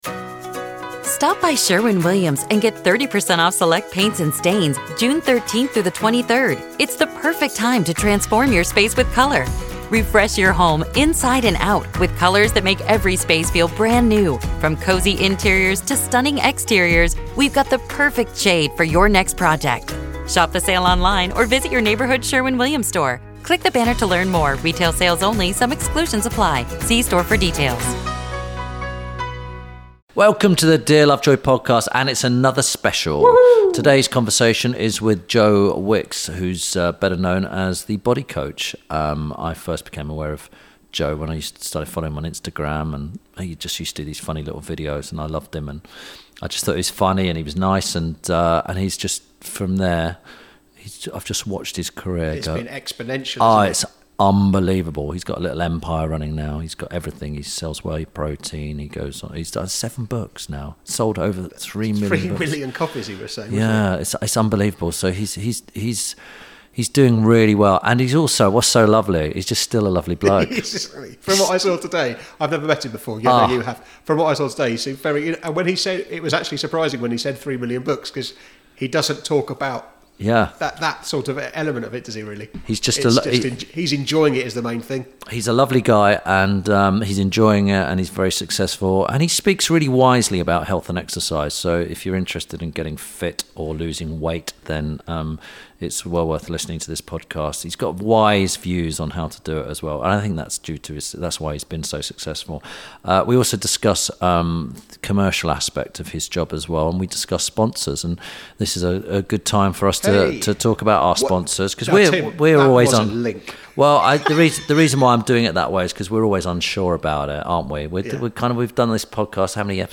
This week Tim Lovejoy talks to body coach, social media sensation and recipe book author, Joe Wicks. They discuss how he grew his passion and ethos into a successful career, the importance of health & fitness in daily life and simple tips on how to improve our well-being.